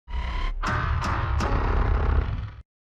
Download Mechagodzilla 2021 laugh sound button
mechagodzilla-laugh-sound-effect-2021-godzilla-vs.mp3